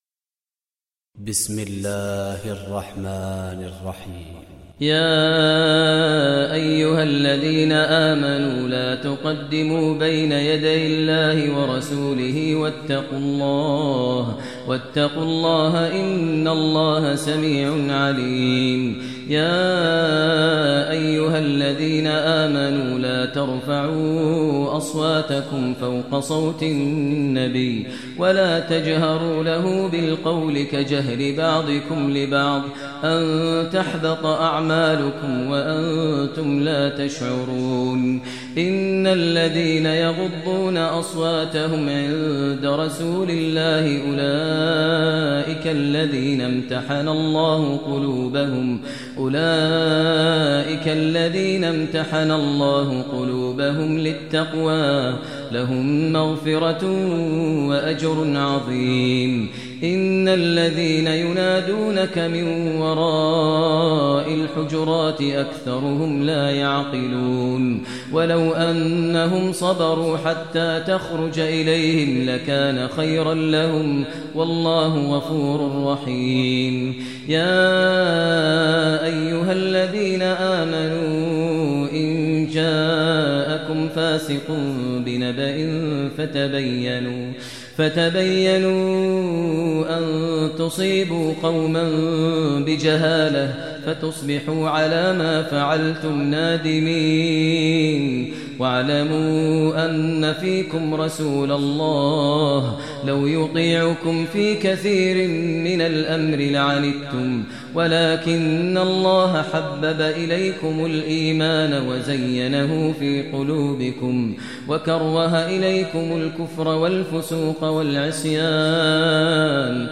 Surah Al-Hujurat Recitation by Maher al Mueaqly
Surah Al-Hujurat, listen online mp3 tilawat / recitation in the voice of Imam e Kaaba Sheikh Maher Al Mueaqly.